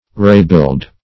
Rebuild \Re*build"\, Re-build \Re-build"\(r[=e]*b[i^]ld"), v. t.